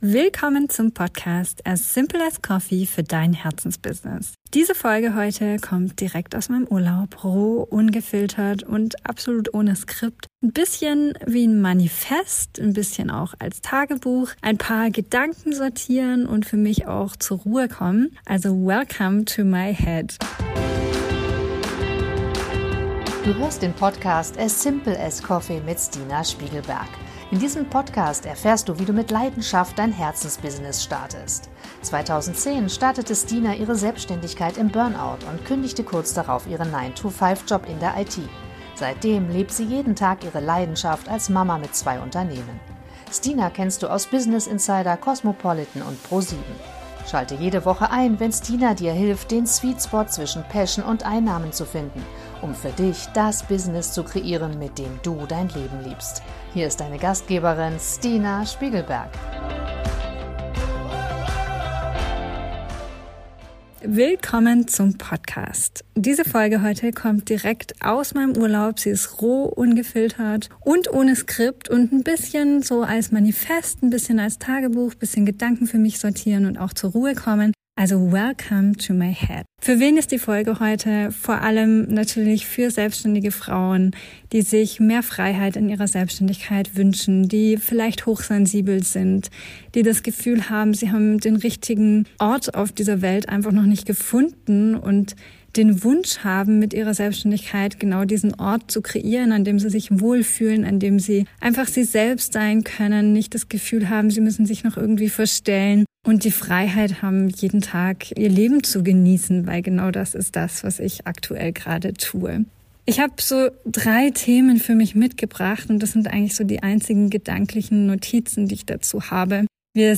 " Diese Folge kommt direkt aus meinem Urlaub, roh, ungefiltert und ohne Skript. Ein Bisschen als Manifest, ein bisschen als Tagebuch, ein bisschen Gedanken sortieren und zur Ruhe kommen.